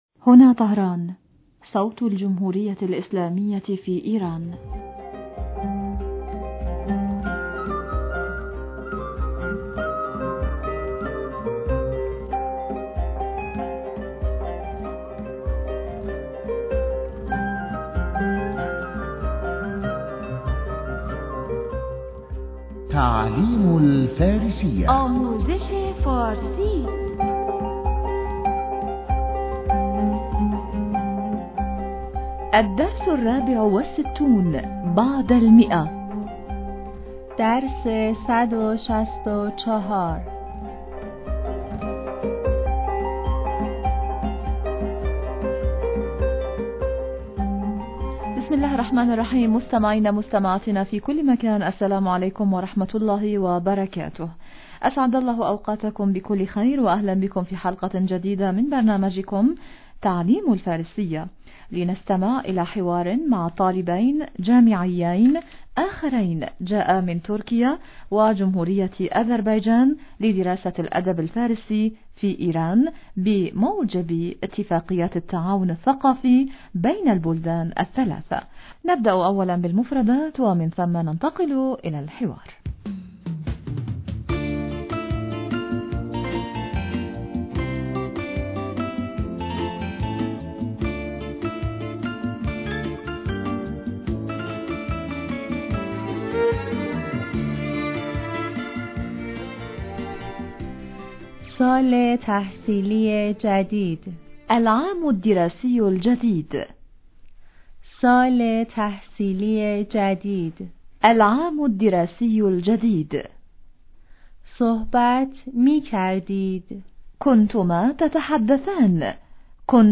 أسعد الله أوقاتكم بكل خير وأهلاً بكم في حلقة جديدة من برنامجكم لنستمع إلي حوار مع طالبين جامعين آخرين جاءا من تركيا وجمهورية أذربيجان لدراسة الأدب الفارسي في ايران بموجب إتفاقيات التعاون الثقافي بين البلدان الثلاثة.